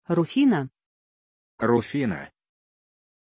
Aussprache von Rufina
pronunciation-rufina-ru.mp3